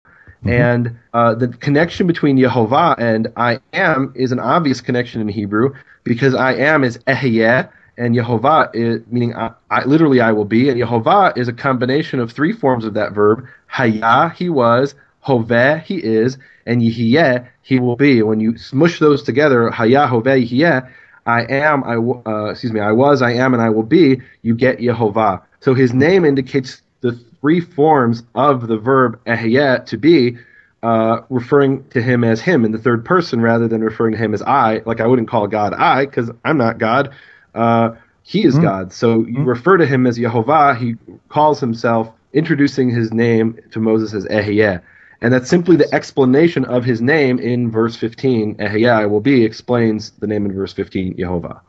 Audio extract here It is a bit quick, but the essence is that God's name is made up from the three tenses of the verb eheyeh - to be.